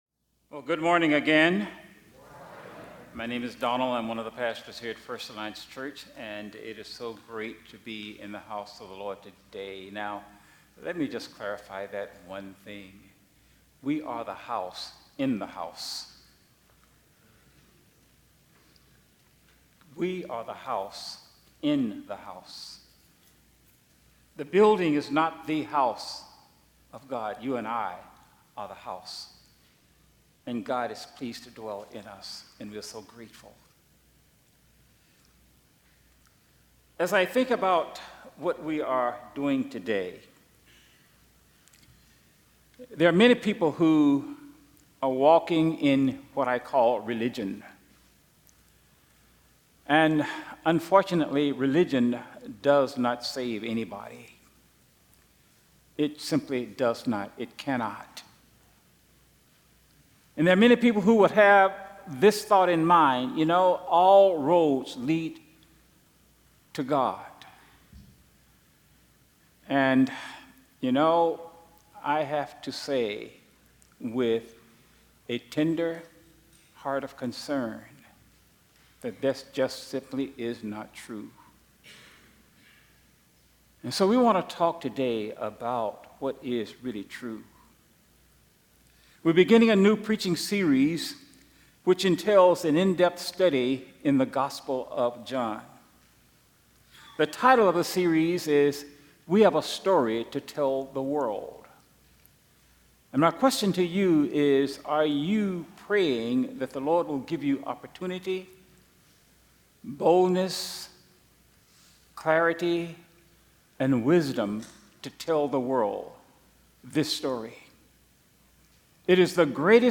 Sermons | First Alliance Church Toledo